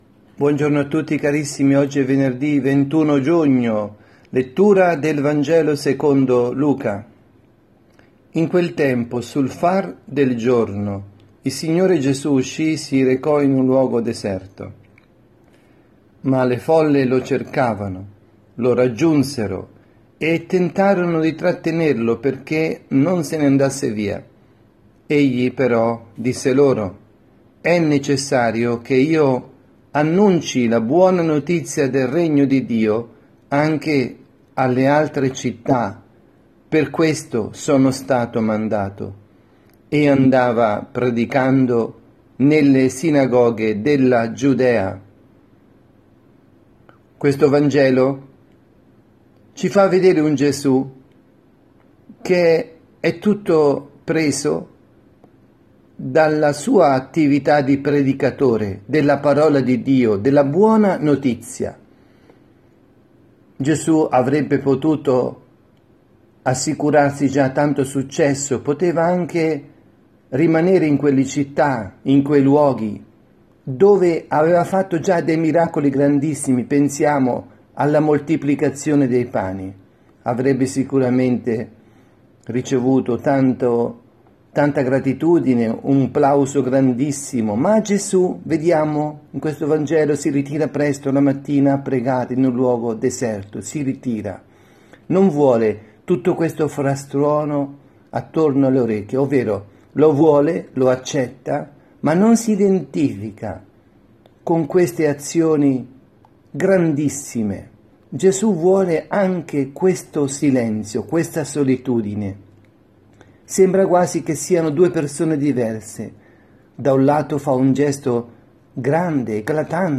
Omelia
dalla Casa di Riposo Santa Marta – Milano